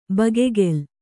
♪ bagegel